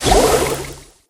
water_jess_atk_01.ogg